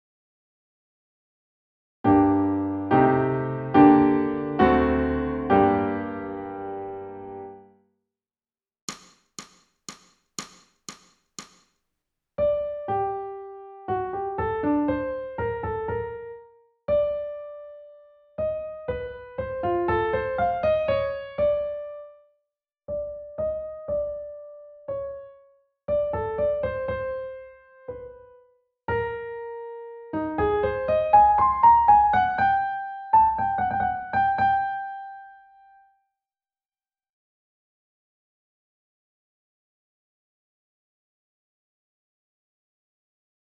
ソルフェージュ 聴音: 2-1-21